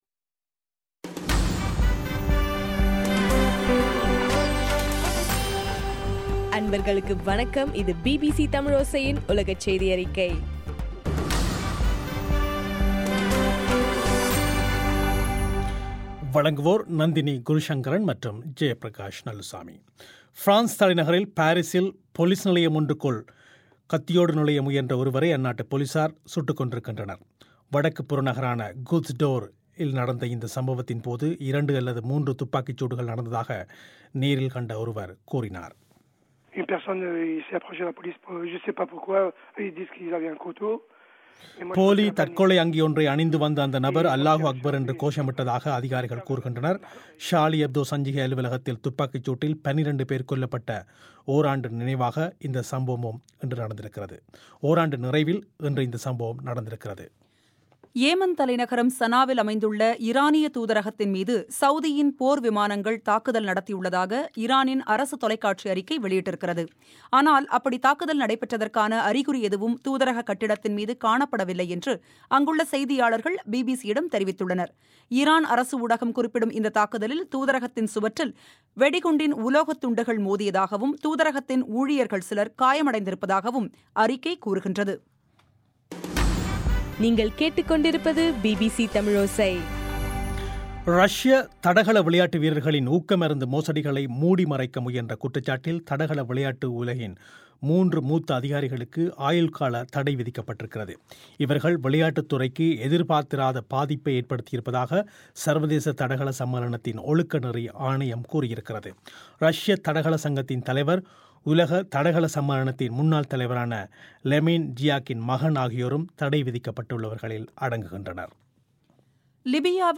ஜனவரி 7 பிபிசியின் உலகச் செய்திகள்